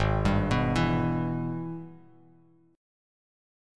G#mM7#5 chord